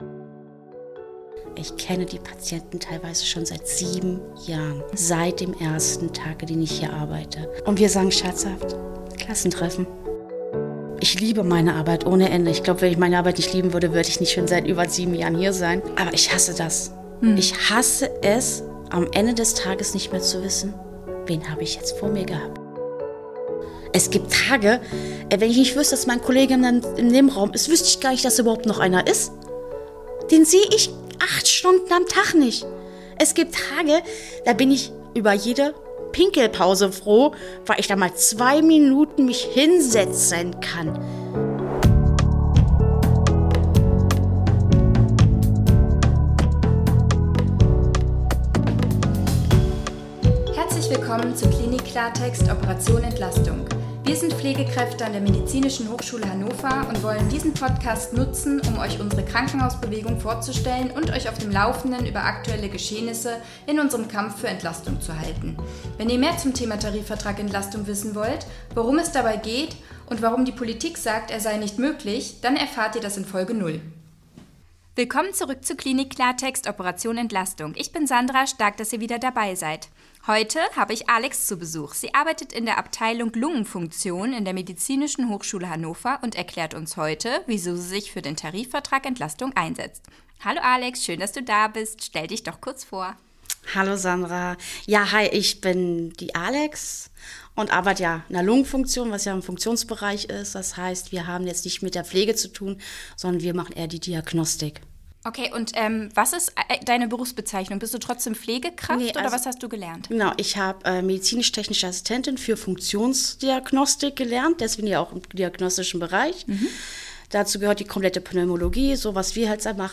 Diese Folge wurde vor der Einigung mit dem Arbeitgeber aufgezeichnet.